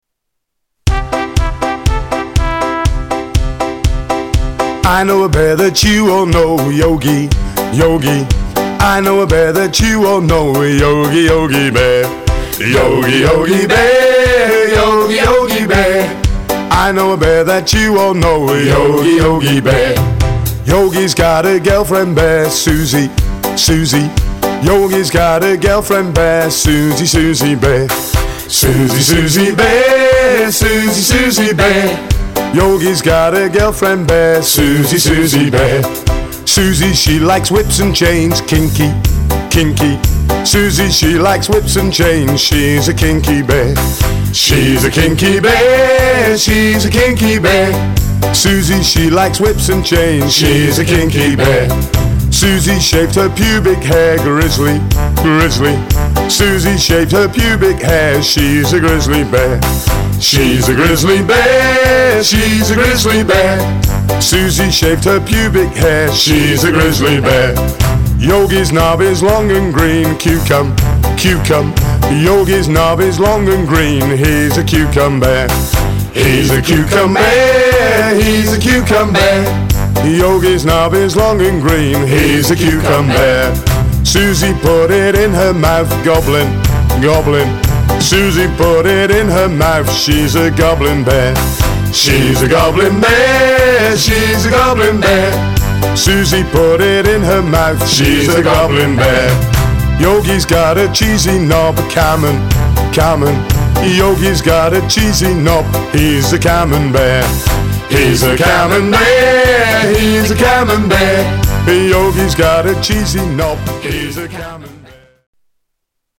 Tags: Media More Parodies Clips Parodies Songs Comedy Spoofs